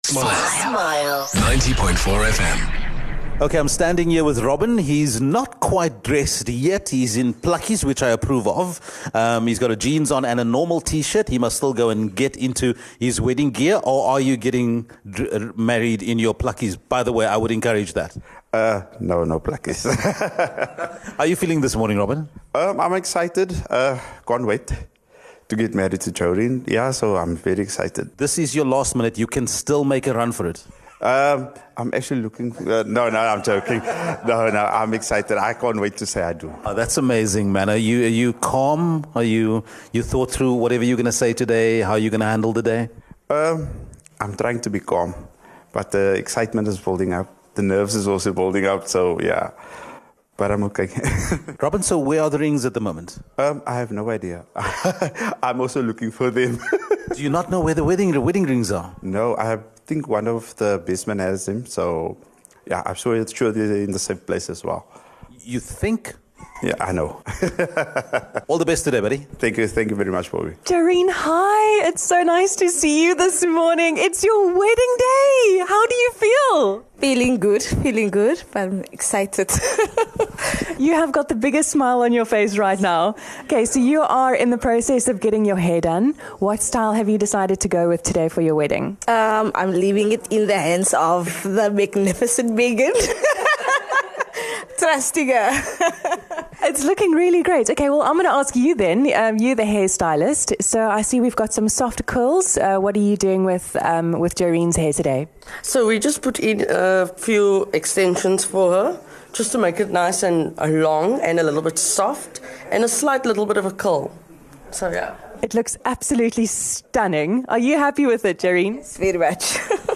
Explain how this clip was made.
Smile Breakfast was live at the wedding venue Rhebokskloof Wine Estate. Before the big moment we caught up with the couple ahead of their vows.